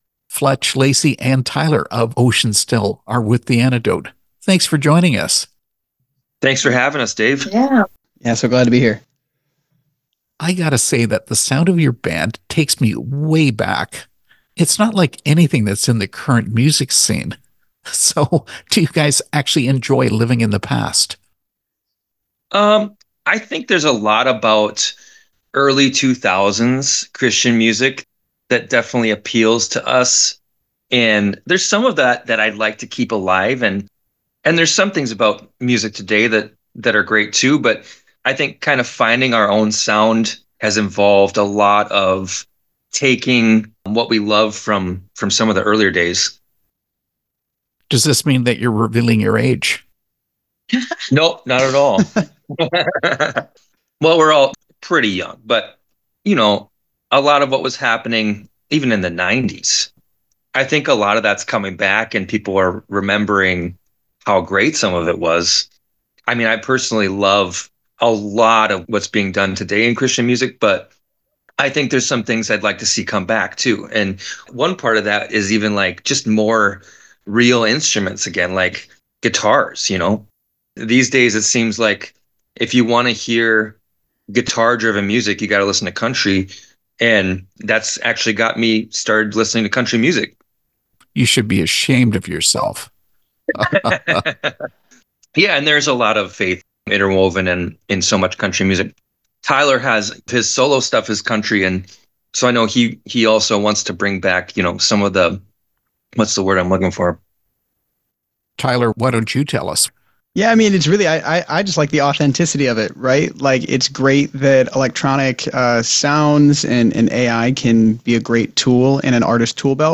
Interview with Oceans Still
oceans-still-interview.mp3